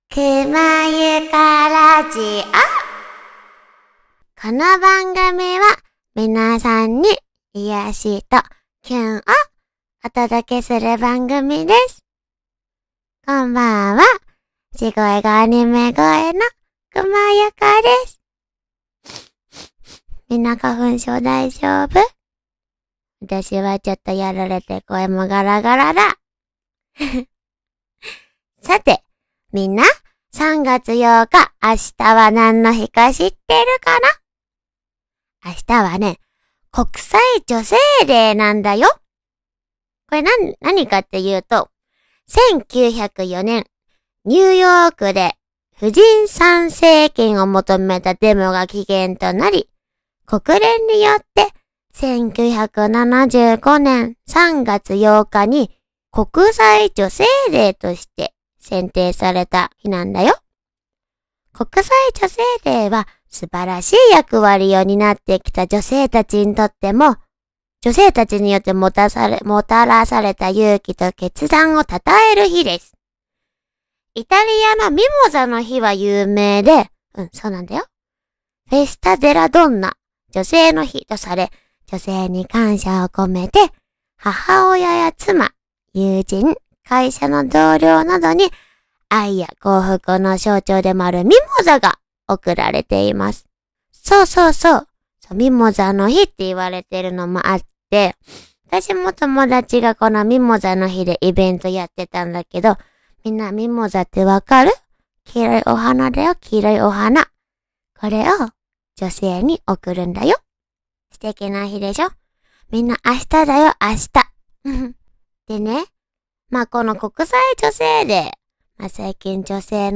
地声がアニメ声🥳
I am Anime Voice